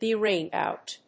アクセントbe ráined óut